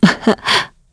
Isolet-Vox_Happy1_kr.wav